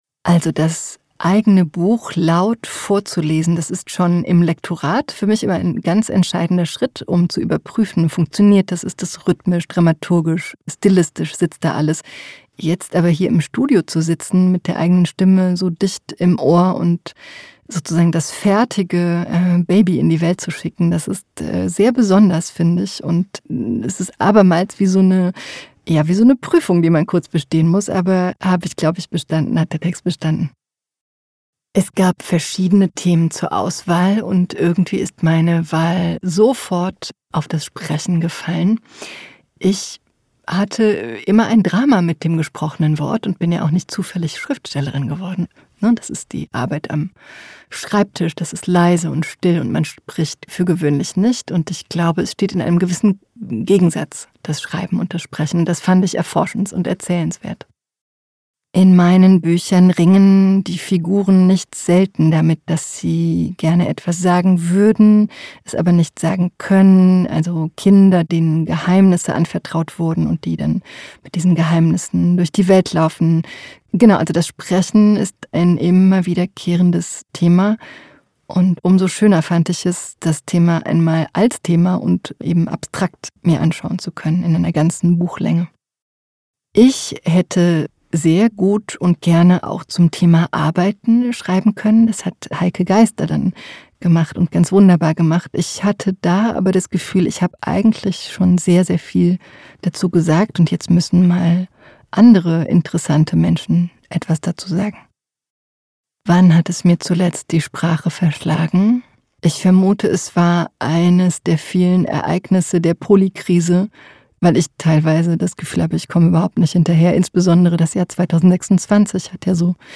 Droescher_Interview_Sprechen.mp3